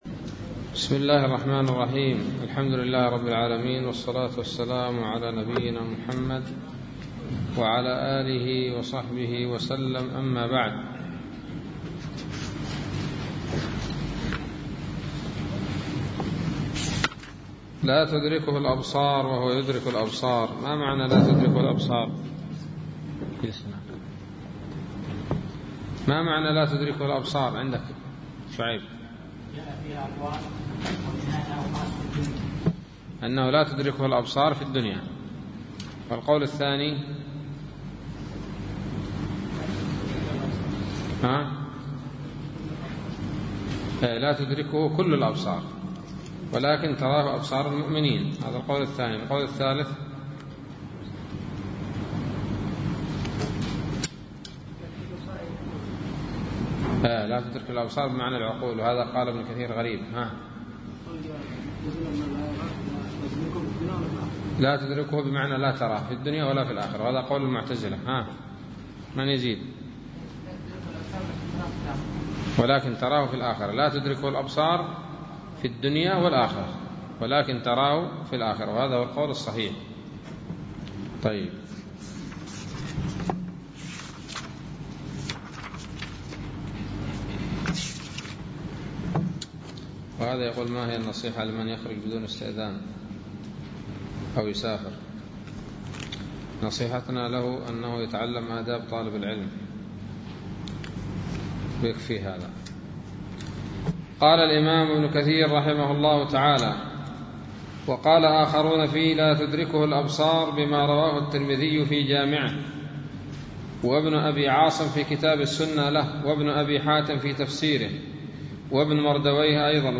الدرس السابع والثلاثون من سورة الأنعام من تفسير ابن كثير رحمه الله تعالى